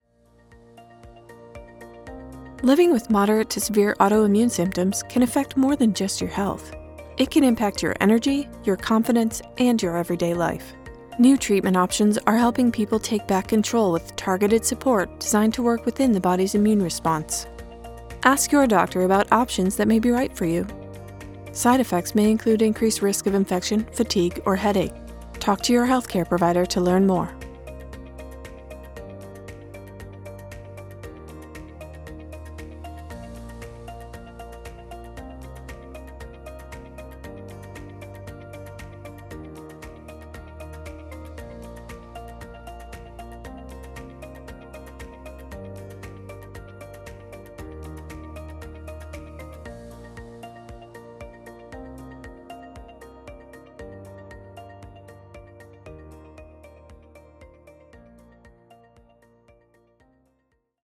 Professional Voice, Personality Included - Female Voice actor with chill vibes, a clear voice with adaptability for your project!
Pharmaceutical commercial conversational believable
Middle Aged